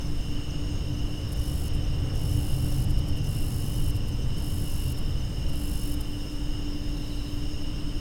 ホシササキリ
細長い体で、か細い声で鳴いていました。 近づいて耳をすまさないと聞こえないほどでした。